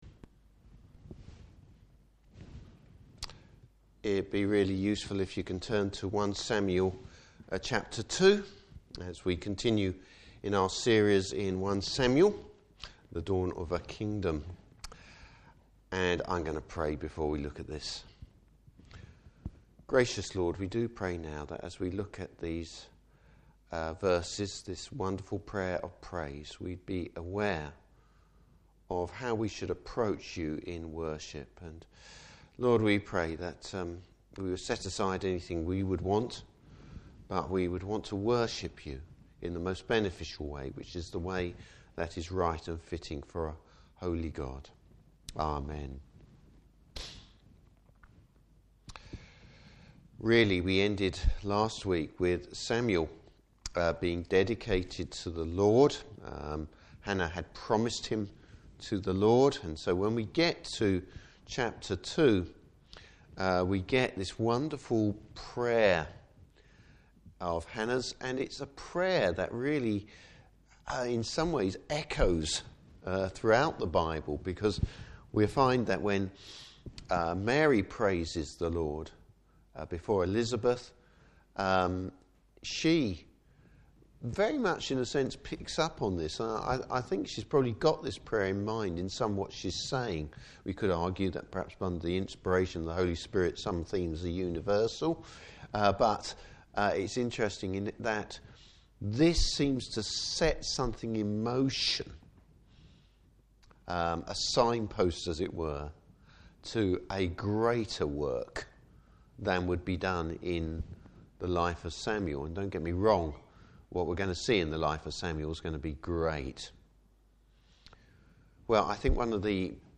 Service Type: Evening Service Why does Hannah praise the Lord?